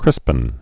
(krĭspən)